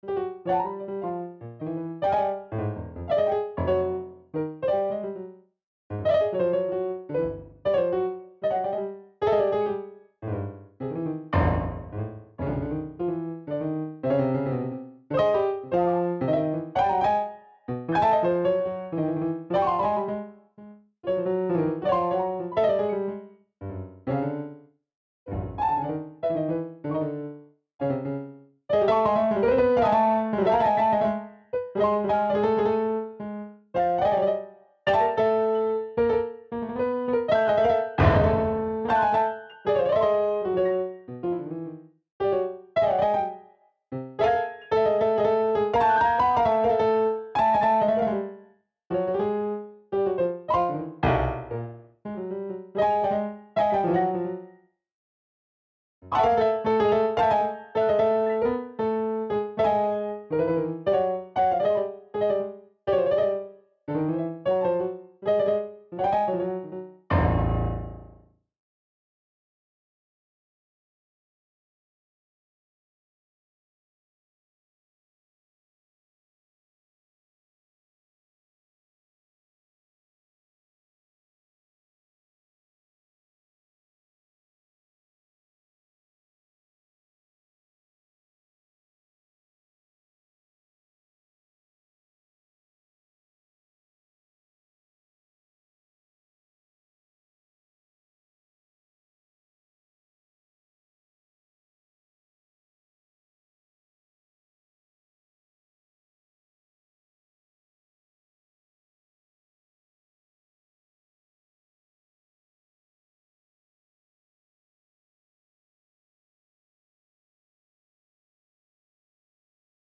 Well, I tried a wav to midi converter…and the results were interesting, to say the least. To my ear the result bears no resemblance to the tune I sang and is more akin to some kind of early twentieth century, dawn of jazz, classical avant garde crossover…have a listen.
bridges-jazz.mp3